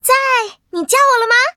文件 文件历史 文件用途 全域文件用途 Fifi_tk_01.ogg （Ogg Vorbis声音文件，长度1.6秒，113 kbps，文件大小：22 KB） 源地址:游戏语音 文件历史 点击某个日期/时间查看对应时刻的文件。